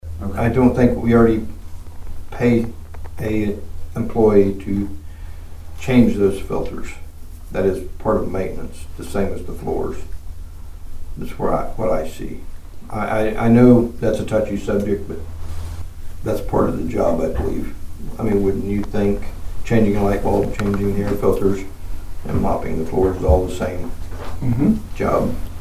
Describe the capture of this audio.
The Nowata County Commissioners met for the first time in February on Monday morning at the Nowata County Annex.